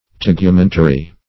Search Result for " tegumentary" : The Collaborative International Dictionary of English v.0.48: Tegumentary \Teg`u*men"ta*ry\, a. [Cf. F. t['e]gumentaire.] Of or pertaining to a tegument or teguments; consisting of teguments; serving as a tegument or covering.